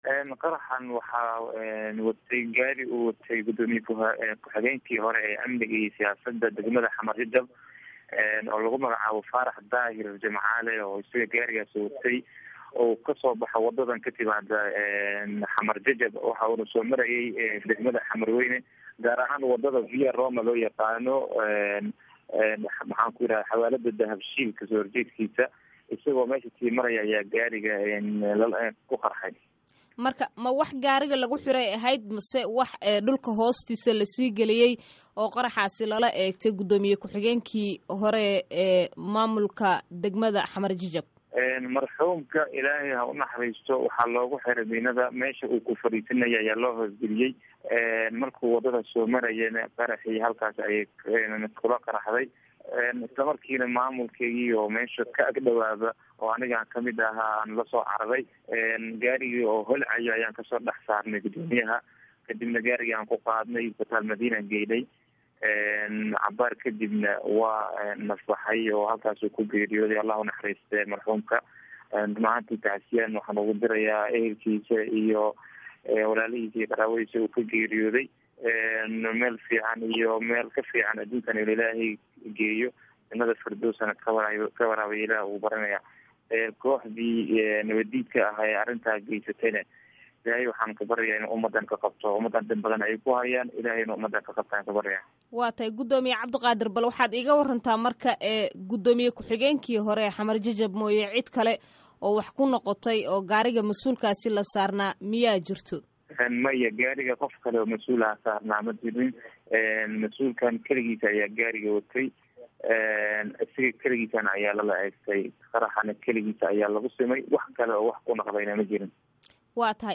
Waraysiga Guddoomiyaha Xamar-Weyne